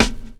kits/RZA/Snares/GVD_snr (21).wav at main
GVD_snr (21).wav